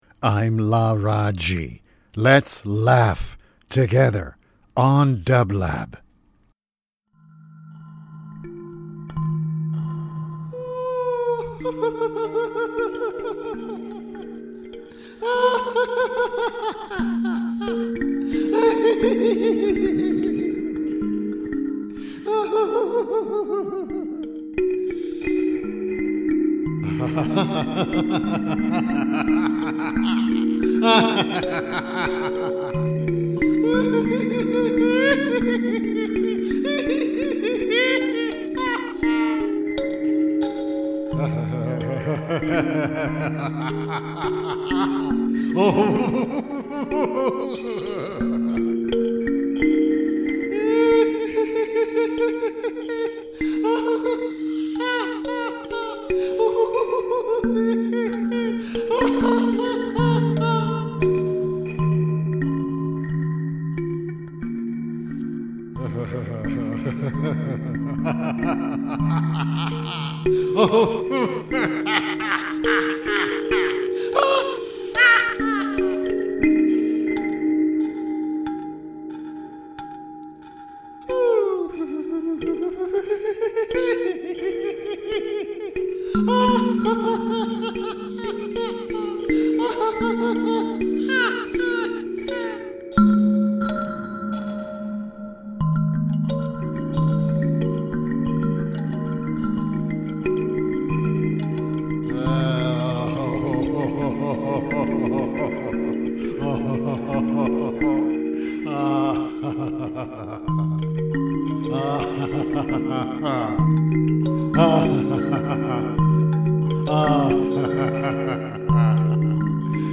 LARAAJI LAUGHS ~ a laughter meditation (04.13.23) - dublab
Every Thursday, Laraaji energizes the dublab airwaves with three minutes of luminous laughter flowing on celestial music beds.